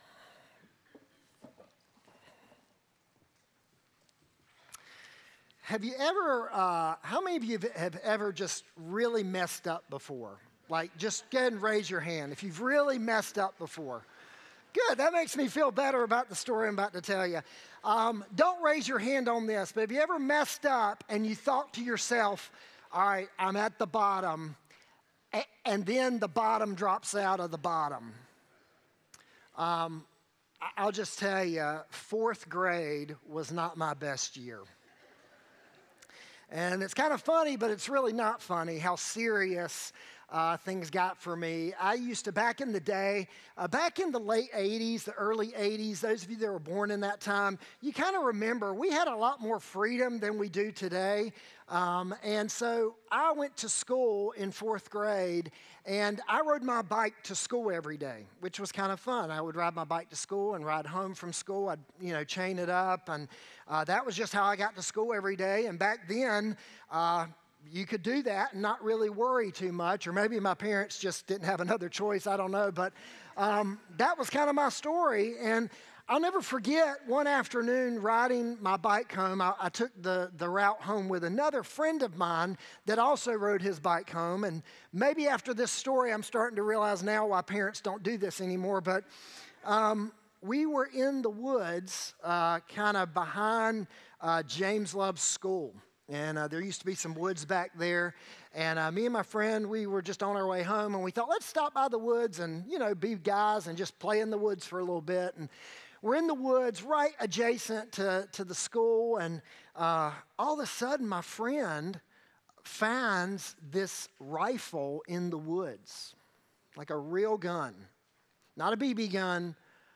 9-21-25-sermon-audio.m4a